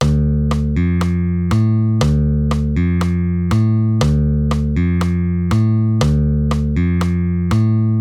Basové figúry – Rumba
Basgitarový hmatník (4 strunová basa)
Rozklad – figúra Dmi dvomi spôsobmi v rytme Rumba.
basa-rumba-Dmi_mp3.mp3